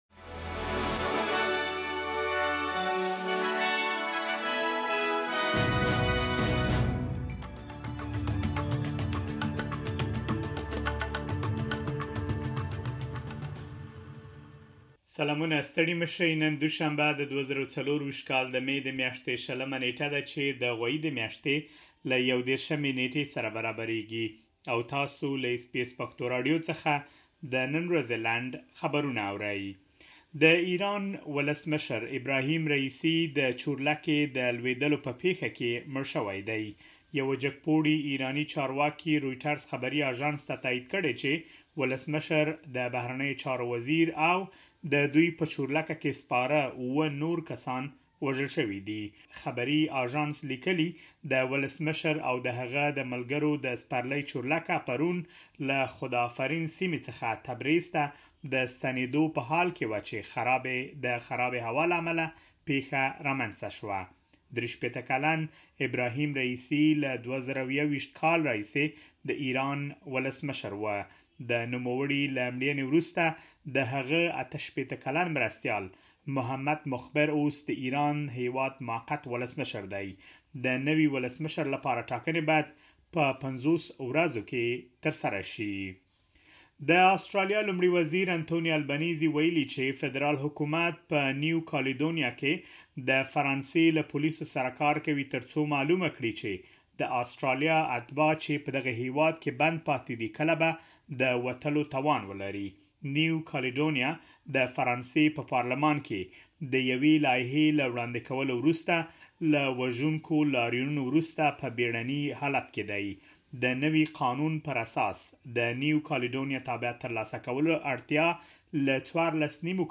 د اس بي اس پښتو د نن ورځې لنډ خبرونه|۲۰ مې ۲۰۲۴